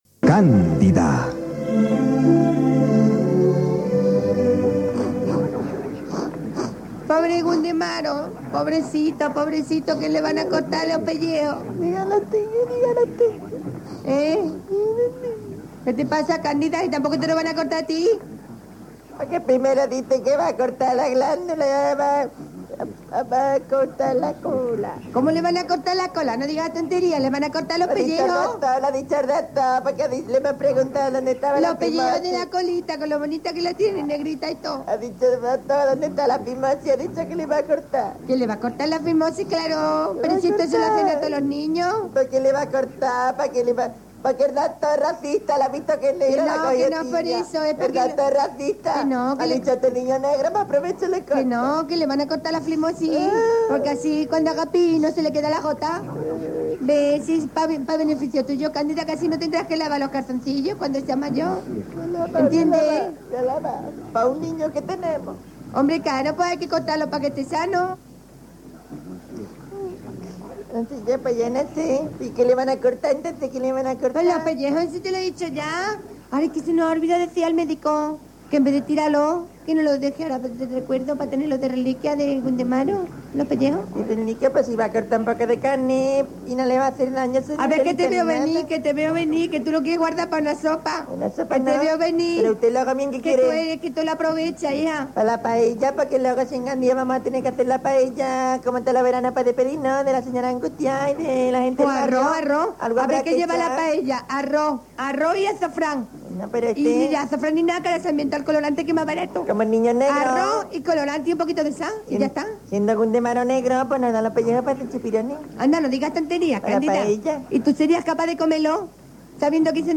Diàleg sobre poder aprofitar uns penjolls de carn d'un nen per fer un arròs, Careta de la sèrie.
Entreteniment
FM